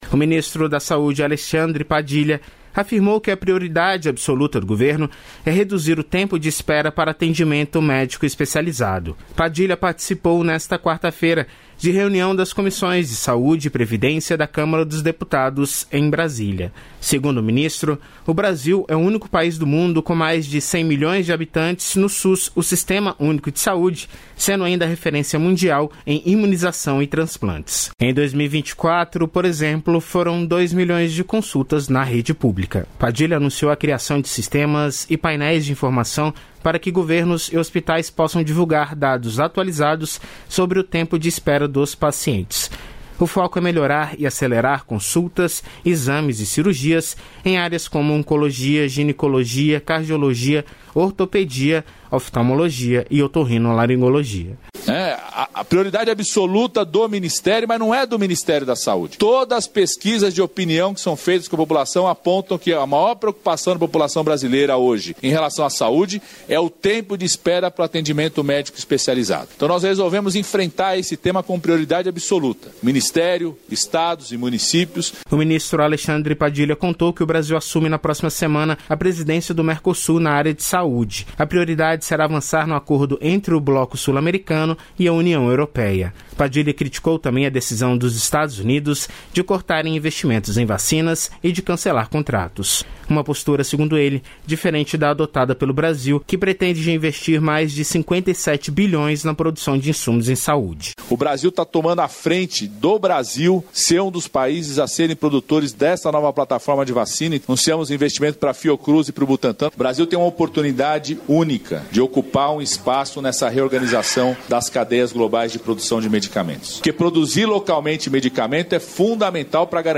Ele participou de reunião das Comissões de Saúde e Previdência da Câmara dos Deputados em Brasília.